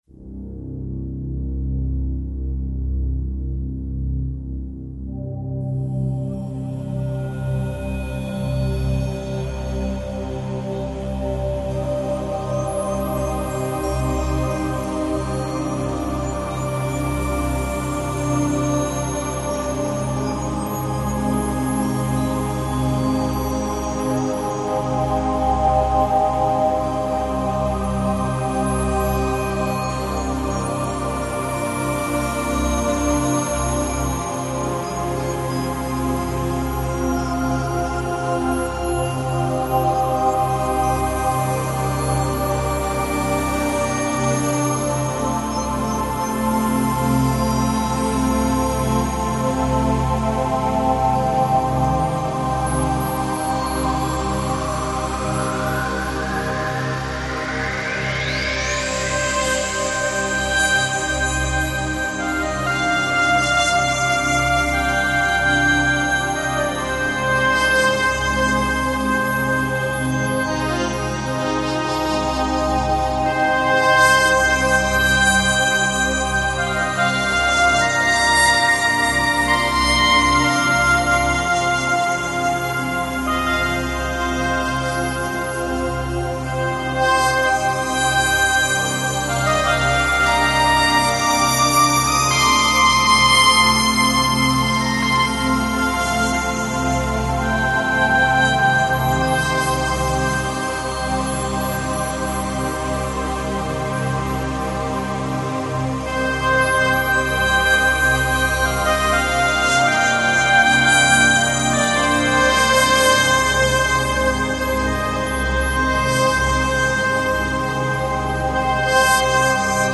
Жанр: New Age